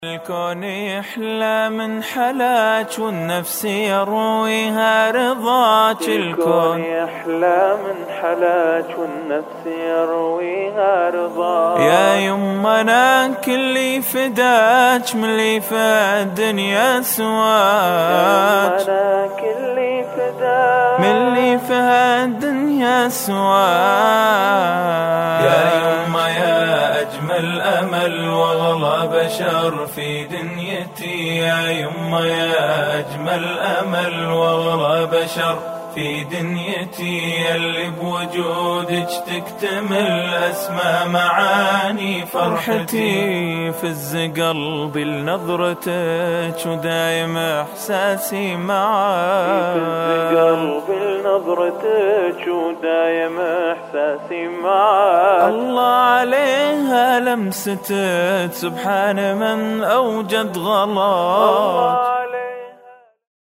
الله يسلمكم هذه نشيدة يديدة بس ما نزلت كاملة..
فعدلت فيها شوي علشان تصلح كنغمة..
أنشودة راااااااااااائعة ,,,